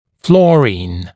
[‘flɔːriːn] [‘fluə-][‘фло:ри:н], [‘флуэ-]фтор